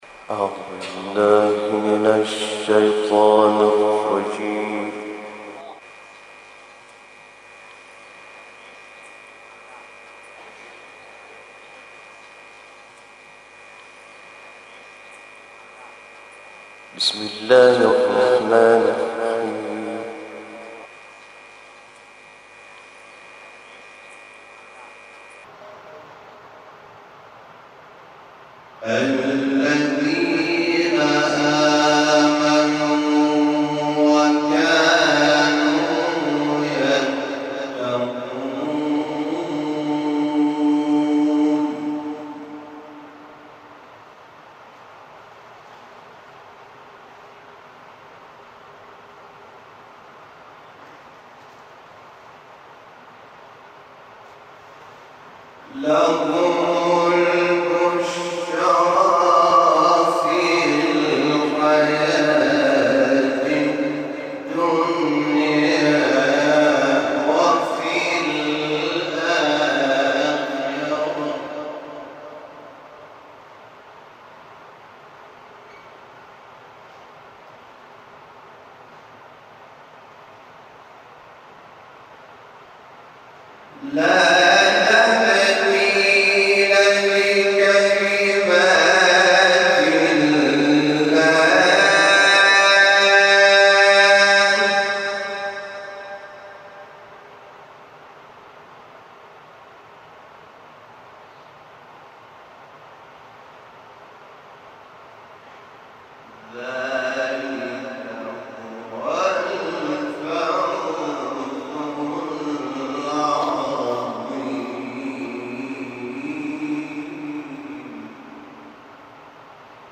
تلاوت
این تلاوت 20 دقیقه‌ای، هفته گذشته در روز شهادت امام صادق(ع) در حسینیه ثارالله(ع) تجریش تهران اجرا شده است.